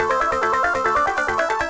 Index of /musicradar/8-bit-bonanza-samples/FM Arp Loops
CS_FMArp A_140-A.wav